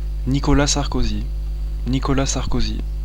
Description “Nicolas Sarkozy” pronounced in native French (recorded with Audacity 1.2.3).